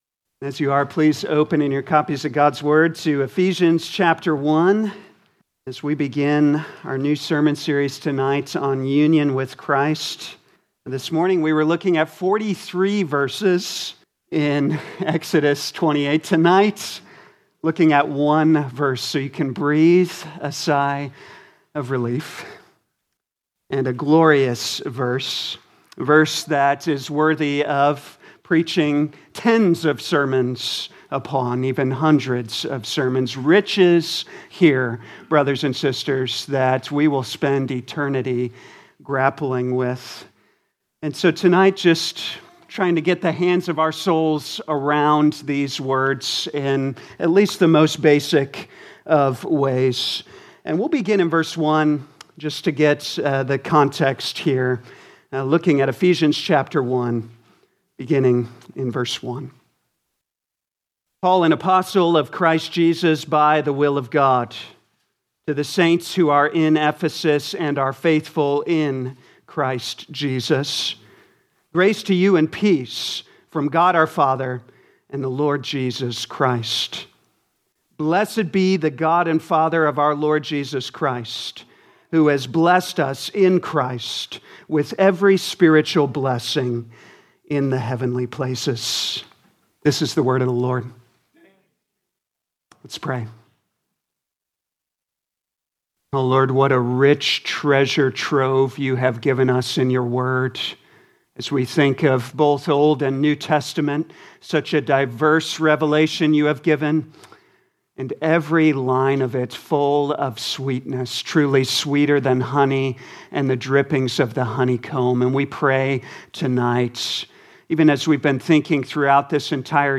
2026 Ephesians Evening Service Download